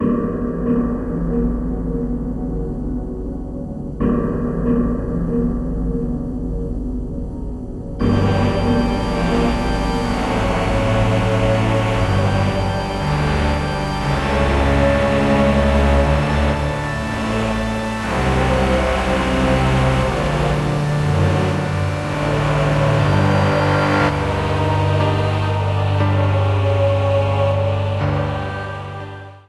Ripped from the game
clipped to 30 seconds and applied fade-out